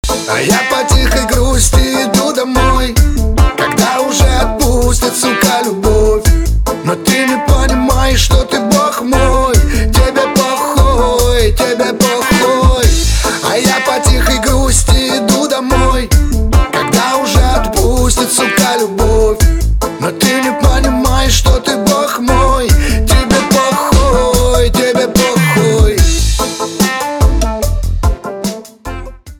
• Качество: 320, Stereo
по тихой грусти